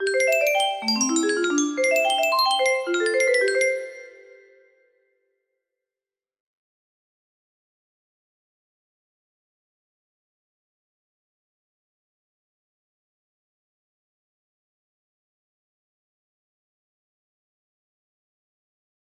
intro 1 music box melody